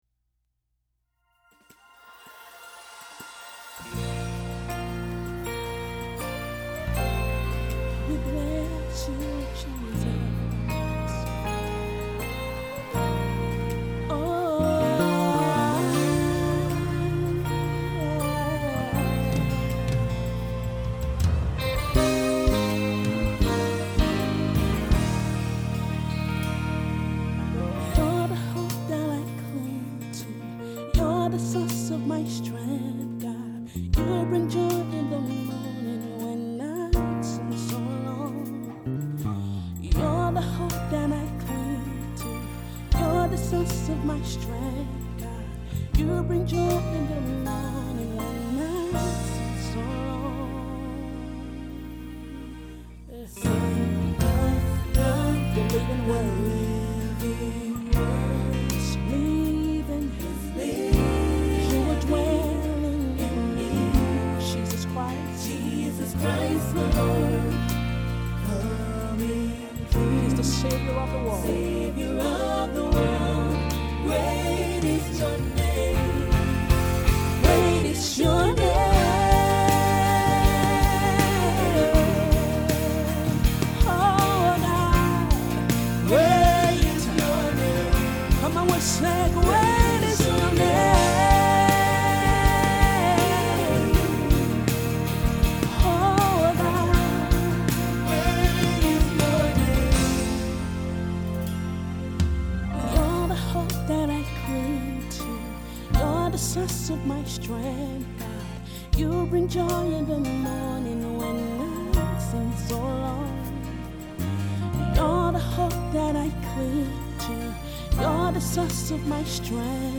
International gospel recording artist/songwriter